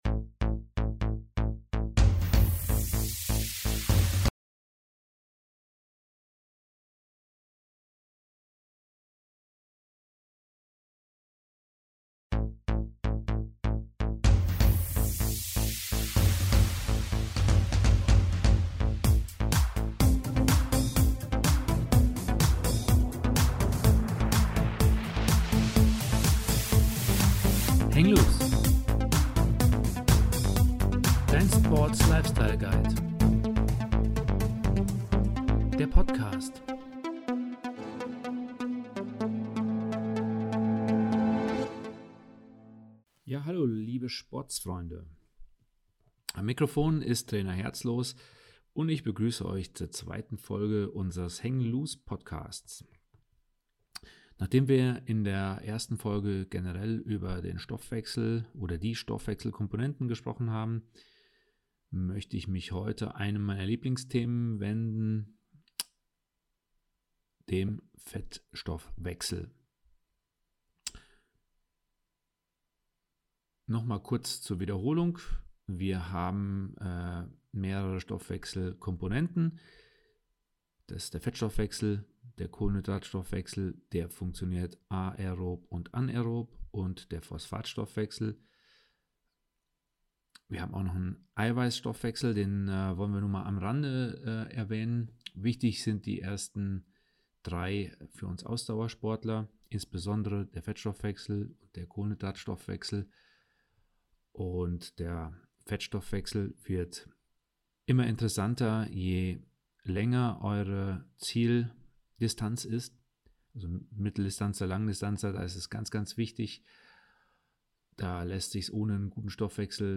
Live Talk
etc. Wir besprechen in lockerem Dialog Tipps und Know-How zum Thema Ernährung im Alltag und Wettkampf Herunterladen